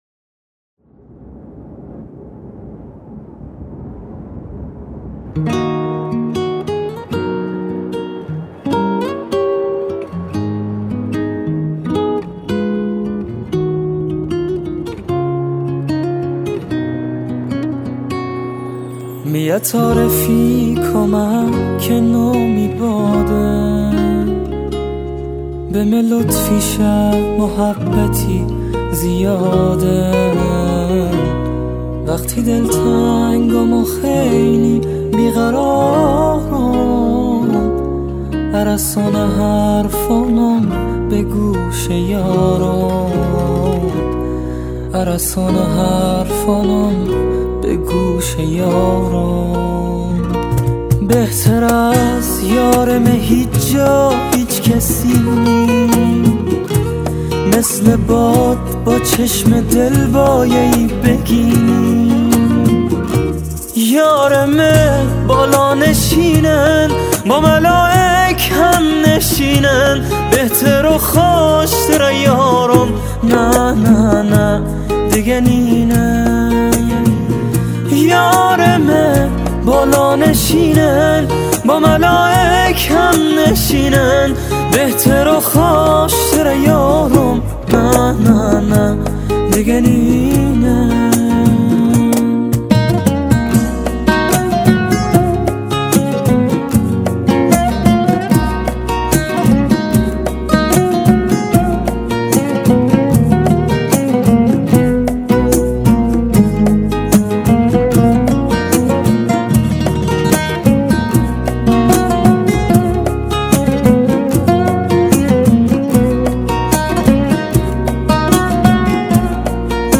دانلود آهنگ بندری